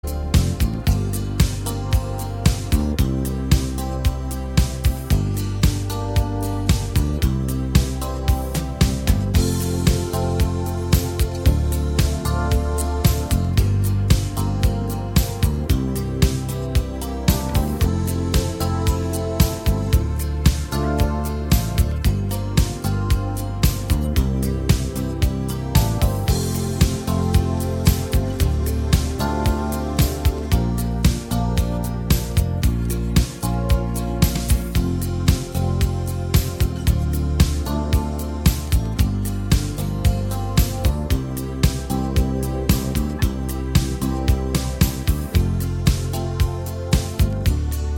Minus All Guitars Pop (1980s) 4:10 Buy £1.50